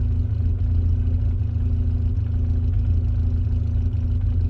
i4_04_idle.wav